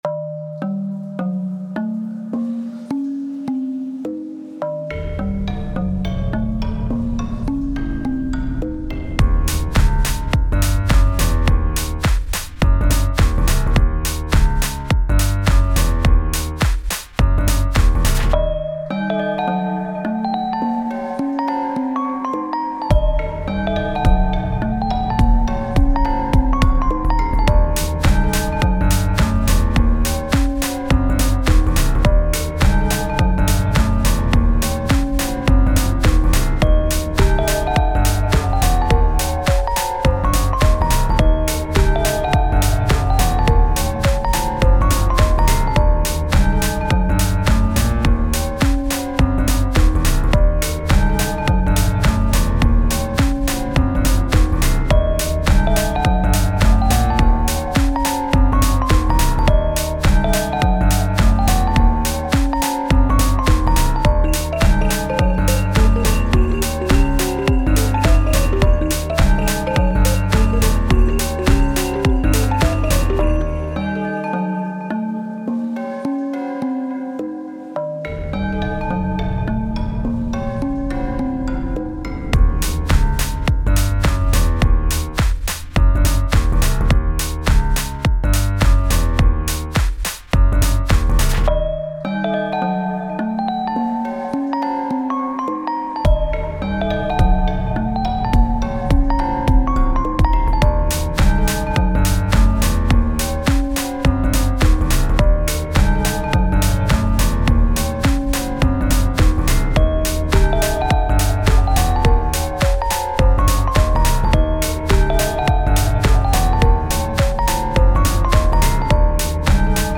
心臓の鼓動のようなタイトなキックに怪しいメロディを合わせることで好奇心と緊張感を演出している。
タグ: ドキドキ/緊張感 フィールド楽曲 冒険 地下/洞窟 コメント: 地下洞窟を探索しているシーンをイメージした楽曲。